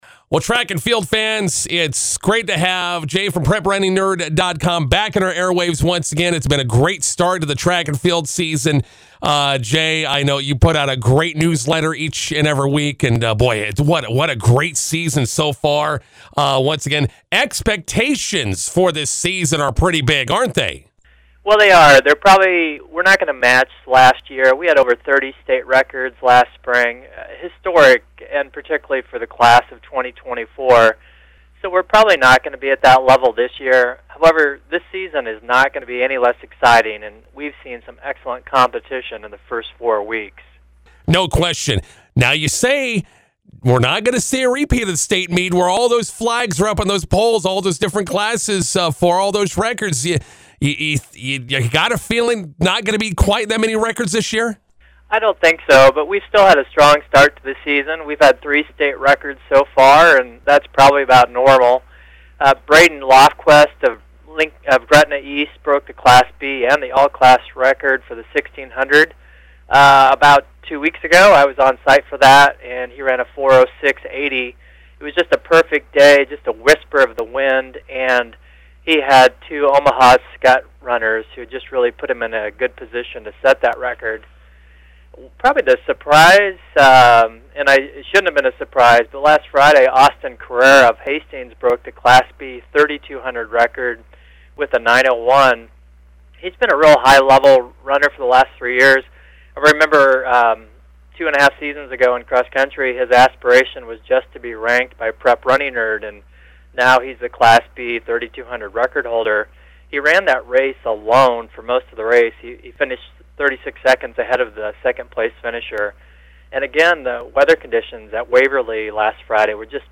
INTERVIEW: Nebraska track and field records being broken every meet.